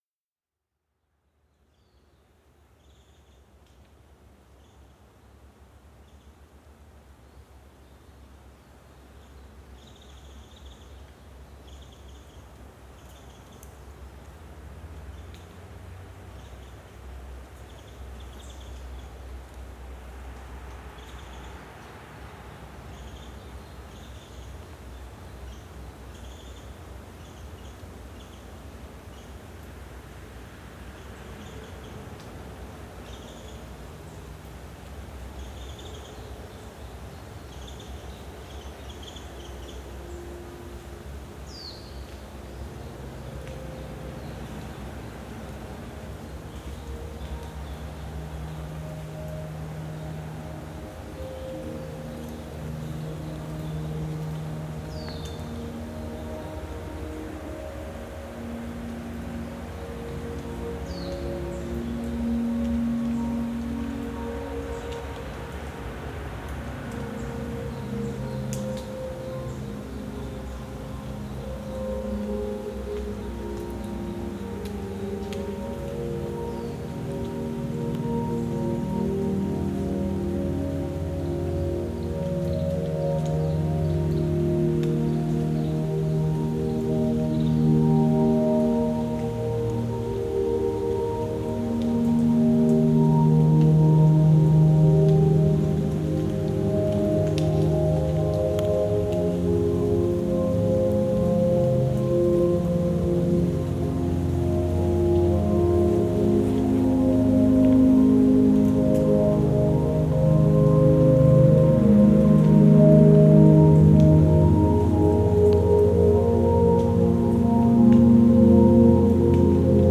Genre: Ambient/Field Recording.